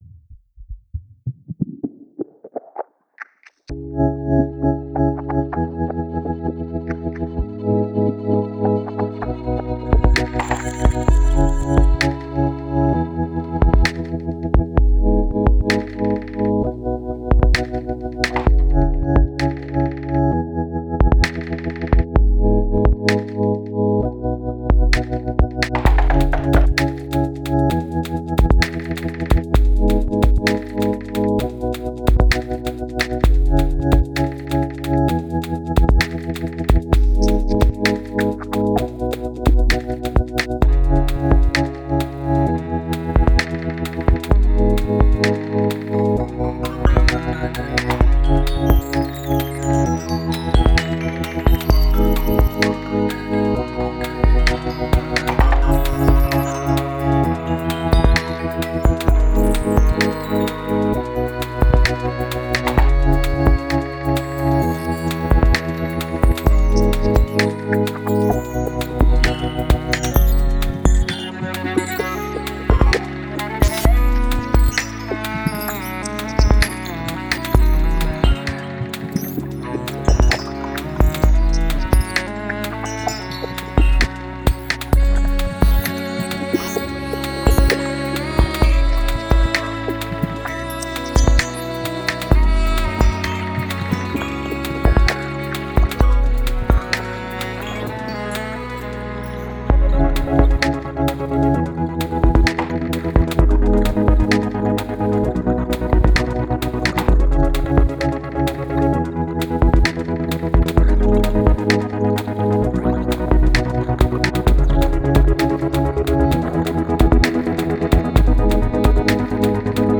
New Age Электронная музыка